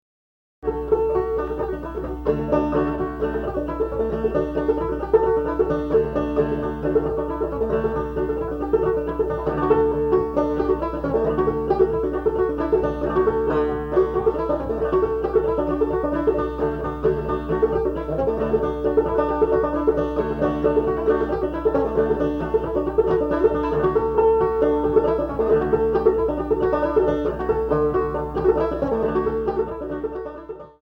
elegant banjo dance tunes
A good example of use of the thumb in the clawhammer style.
banjo